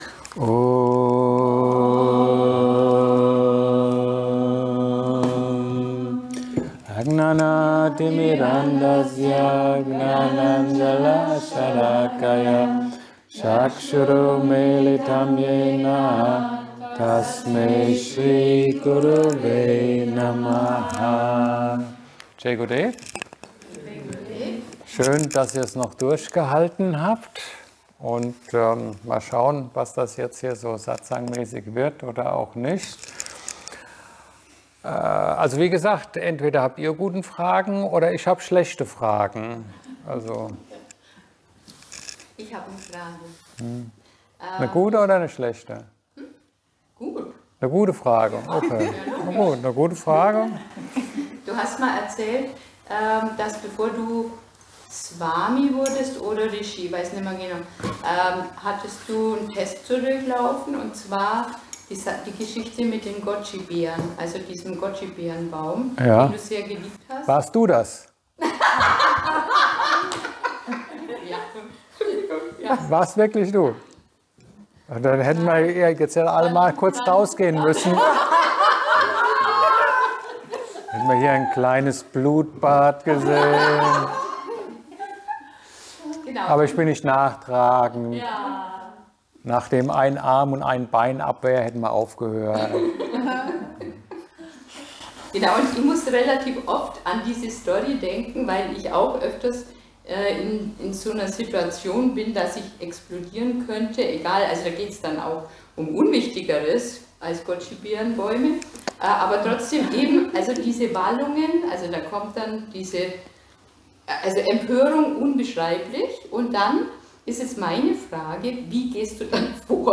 Im Zentrum White Horse haben wir eine Tiefsinnige Unterhaltung geführt.